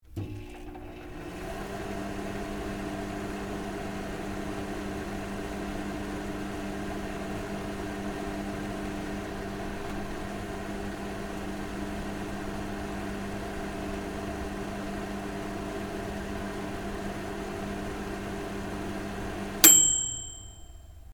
针对关上微波炉音效的PPT演示模板_风云办公